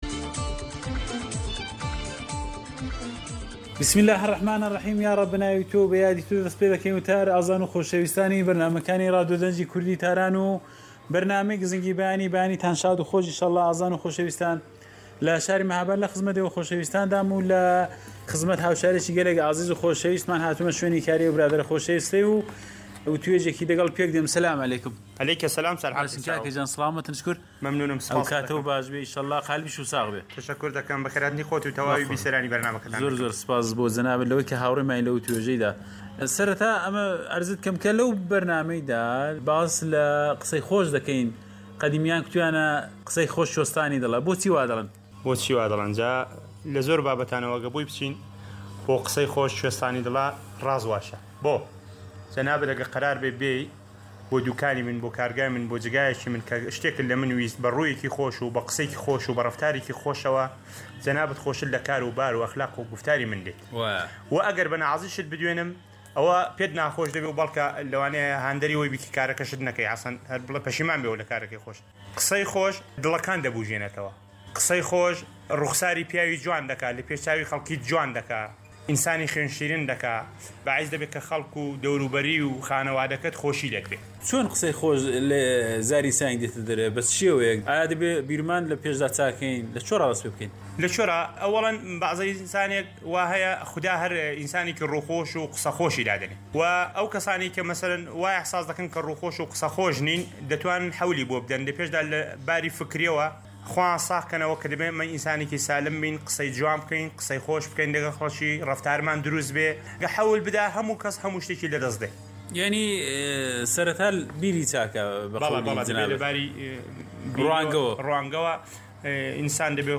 وتووێژێک له شاری مەهاباد سەبارەت به قسەی خۆش